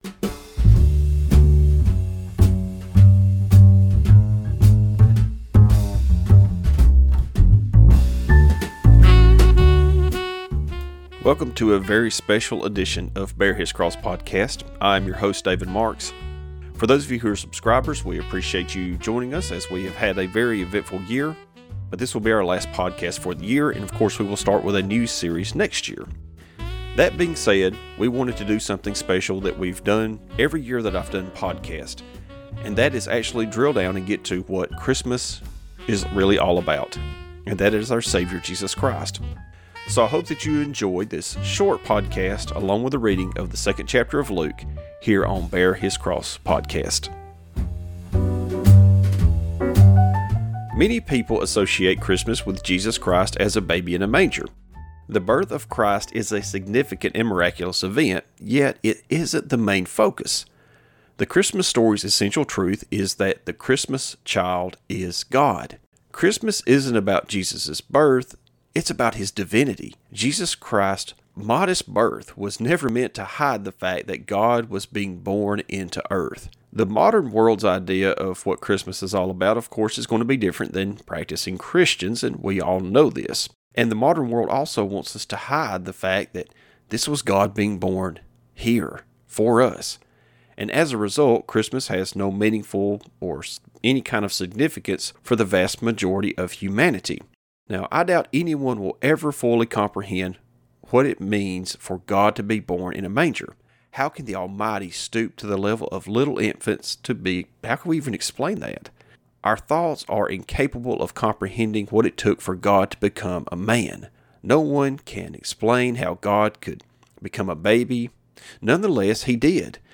Commentary and reading from Luke Chapter 2.